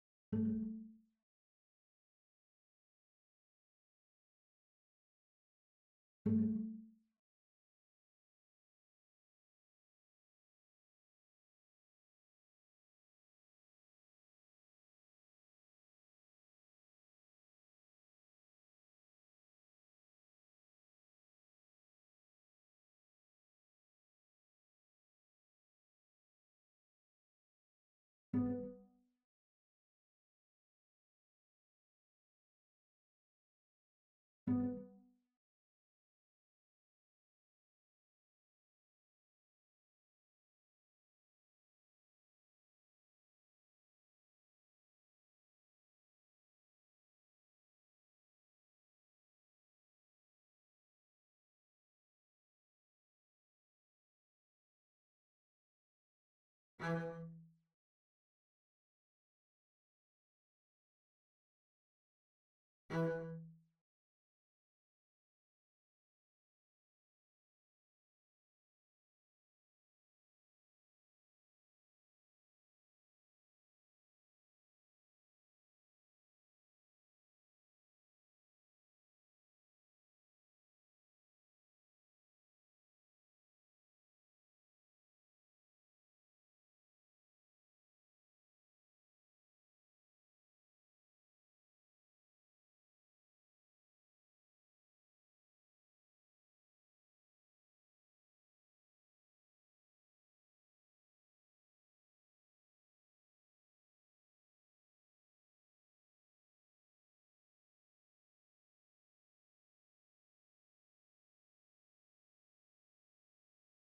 04-Rondo-07-Cello_2.mp3